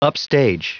Prononciation du mot upstage en anglais (fichier audio)